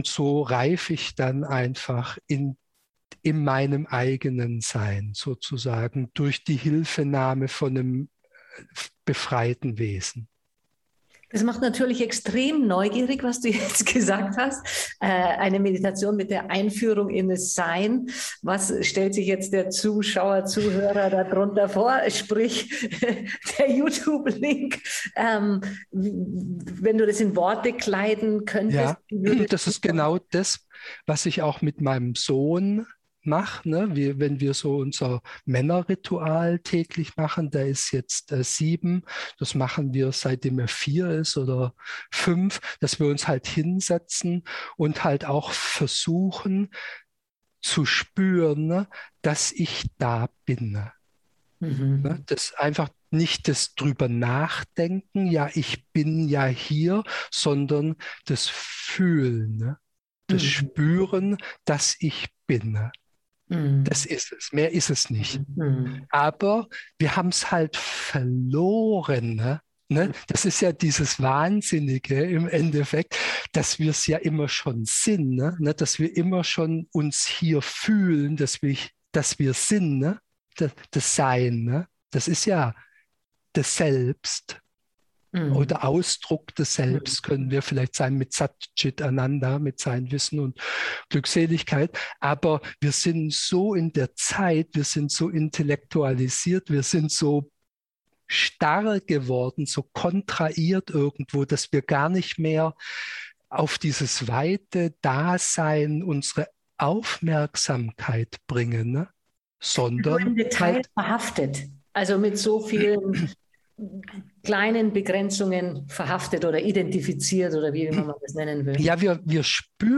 So unterstützen dich die Interviews bei der Vertiefung deiner eigenen Meditationspraxis.